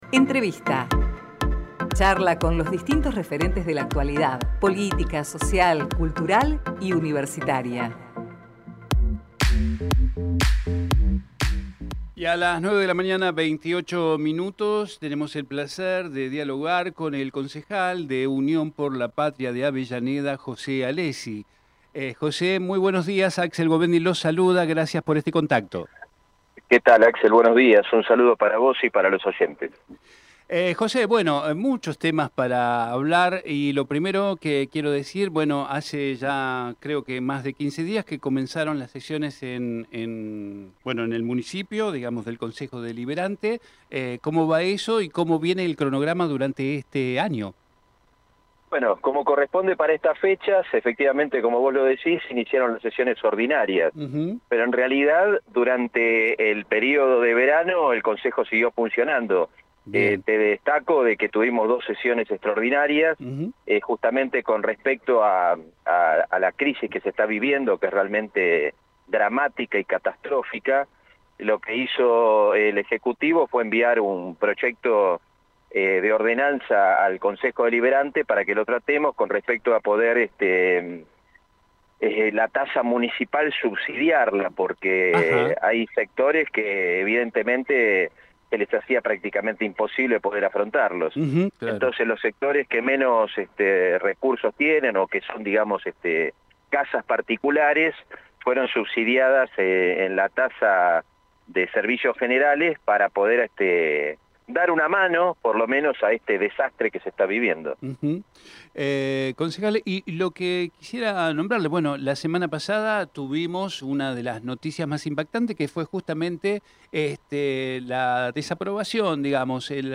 TERRITORIO SUR - JOSÉ ALESSI Texto de la nota: Compartimos la entrevista realizada en Territorio Sur al Concejal de Unión por la Patria de Avellaneda José Alessi.